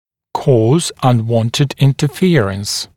[kɔːz ʌn’wɔntɪd ˌɪntə’fɪərəns][‘ко:з ан’уонтид ˌинтэ’фиэрэнс]быть причиной возникновения нежелательных контактов, вызывать нежелательне контакты